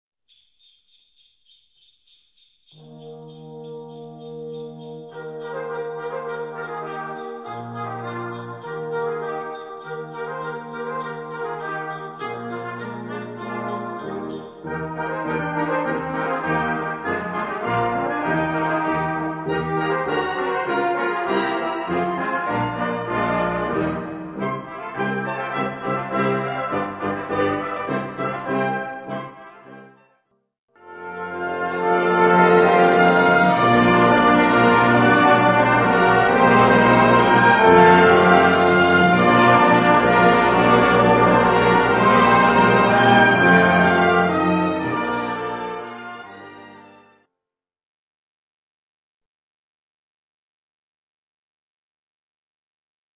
Gattung: Weihnachtsmusik
Besetzung: Blasorchester